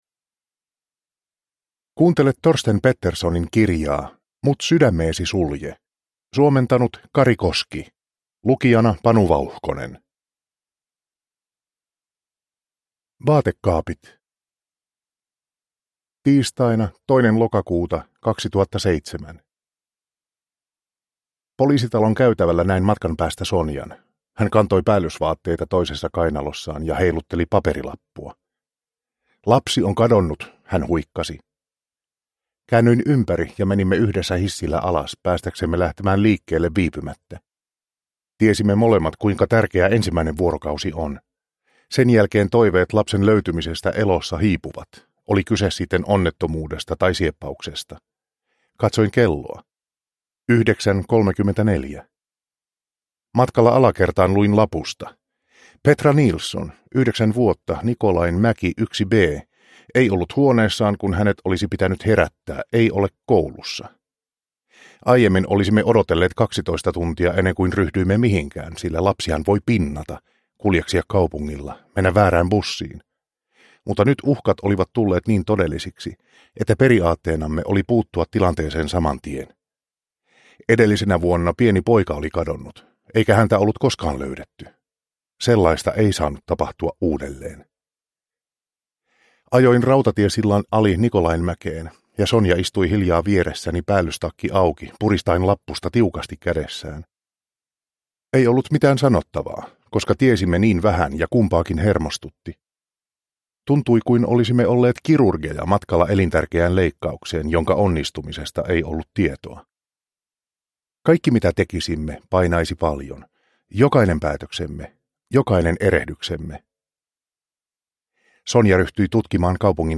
Mut sydämeesi sulje – Ljudbok – Laddas ner